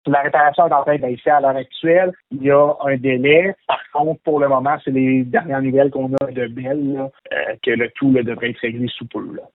Selon les explications du maire de Cayamant, Nicolas Malette, des équipes techniques sont présentement sur place pour réparer les fibres endommagées :